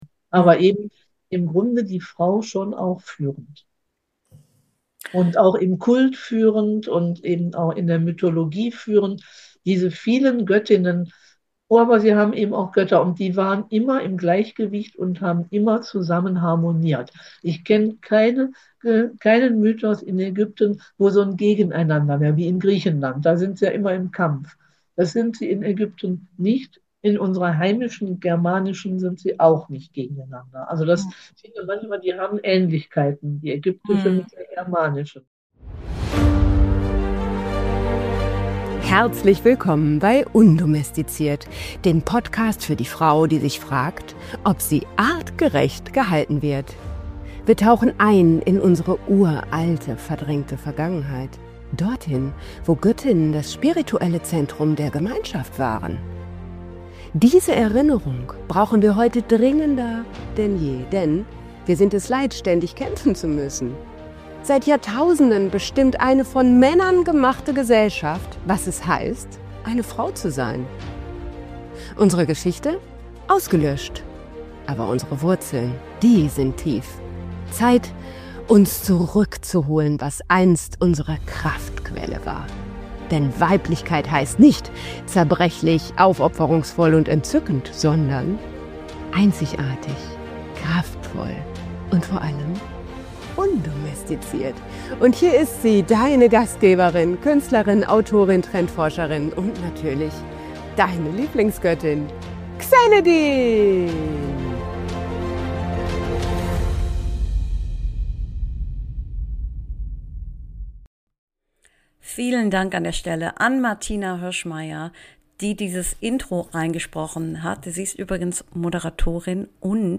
#64 Vom Mythos zur Mission: Die Ägyptische Isis und die Rückkehr der Göttinnen - Interview